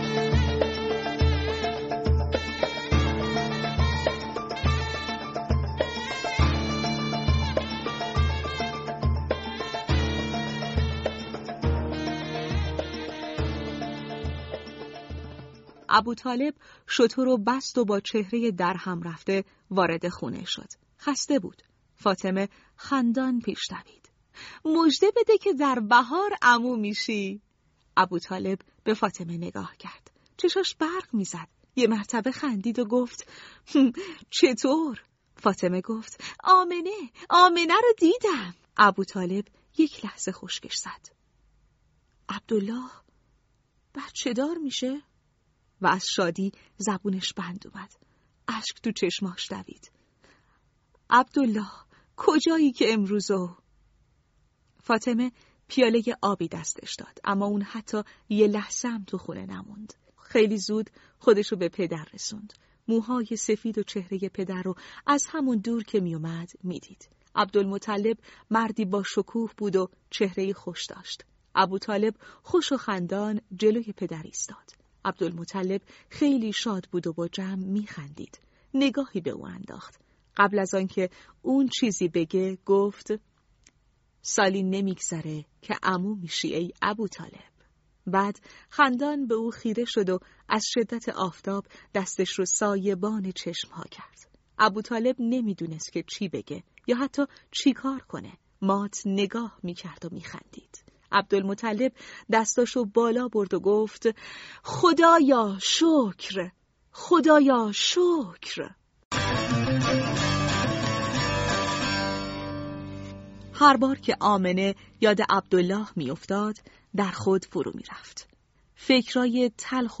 دانلود صوت بفرمایید قصه کتاب صوتی «۳۶۶ روز با پیامبر عزیزمان» فصل ششم راوی
# کتاب صوتی # قصه کودک # پیامبر اکرم (صلی الله علیه و آله) # یه صفحه کتاب